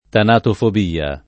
tanatofobia [ tanatofob & a ] s. f. (med.)